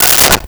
Plastic Plate 03
Plastic Plate 03.wav